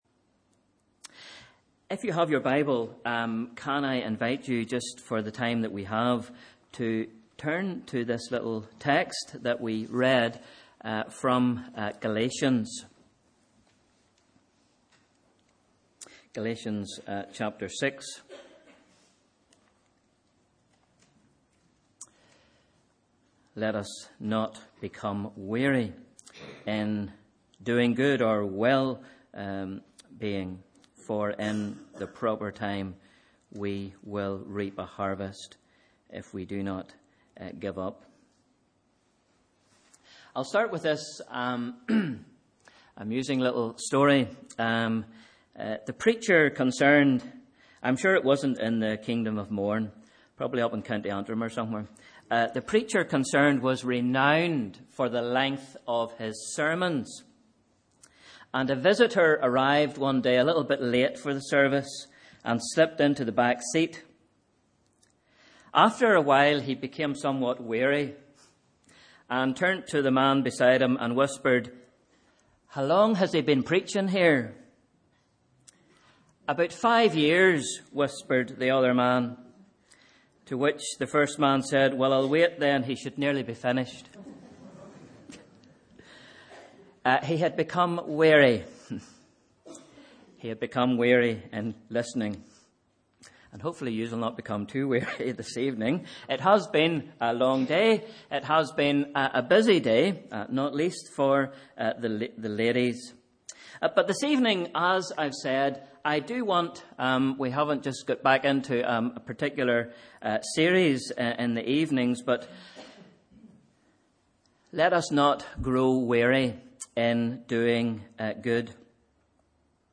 Sunday 29th April 2018 – Evening Service